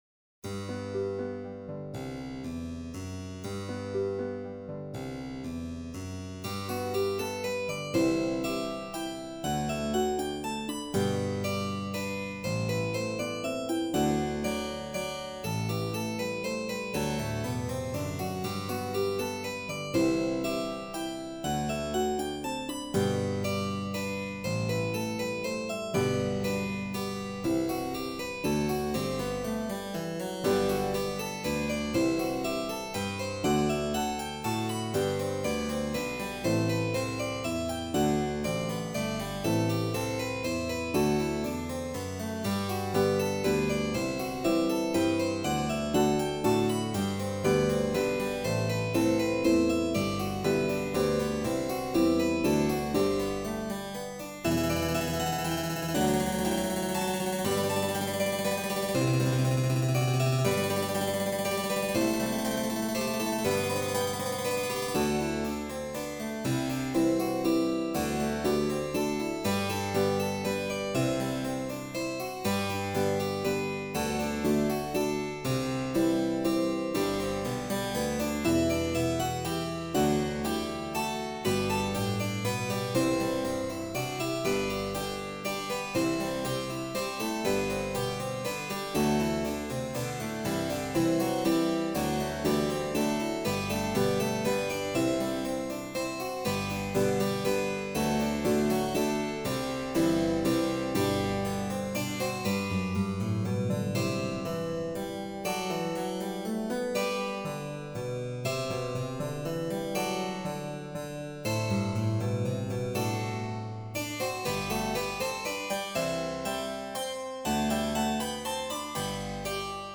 (for mandolin orchestra)
Halfway Home Computer recording
HalfHome-MandoOrch.mp3